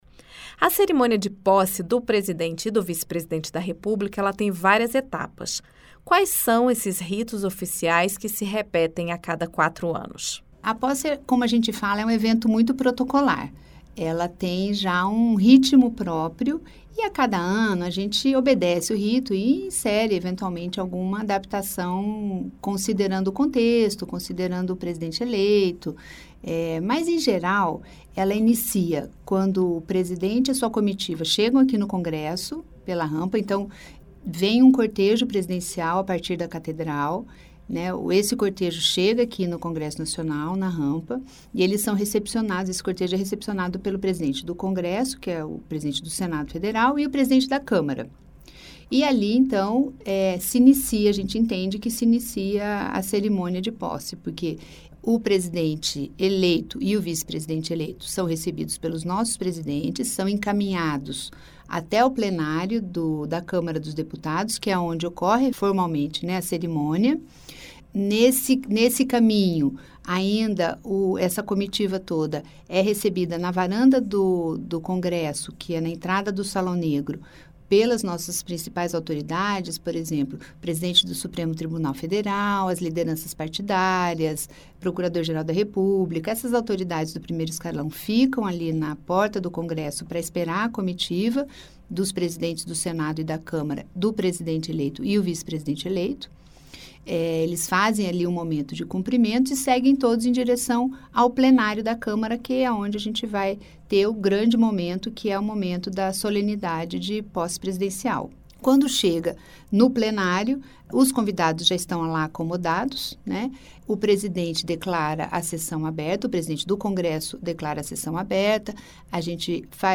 Acompanhe a entrevista e entenda os detalhes institucionais da cerimônia.